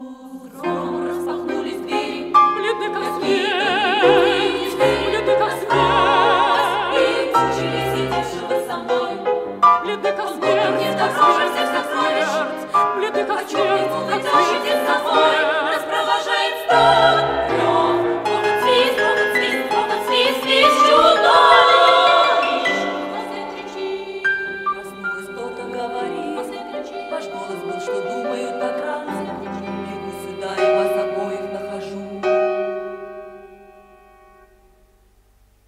ария Софьи